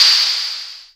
Index of /VEE/VEE2 Cymbals/VEE2 Rides
VEE2 Ride 18.wav